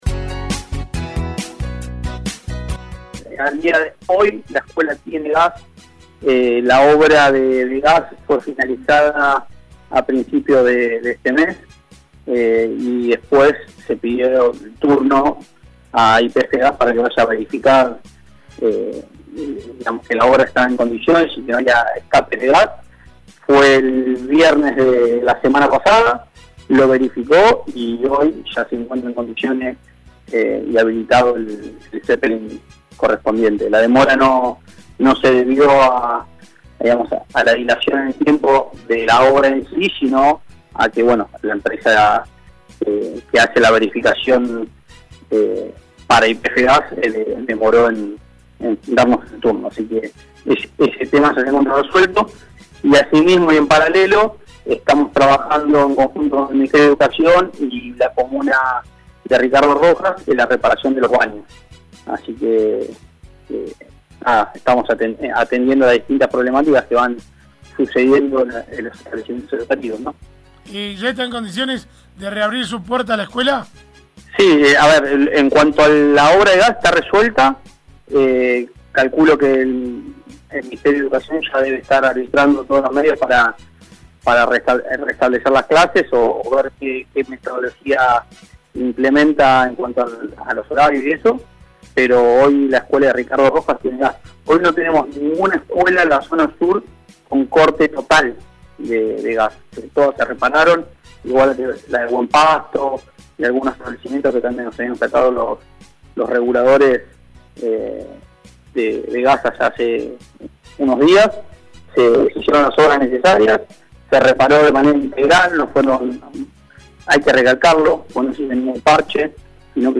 Desde el gobierno de Chubut, la respuesta vino en la voz del Secretario de Infraestructura, Energía y Planificación Hernán Tórtola: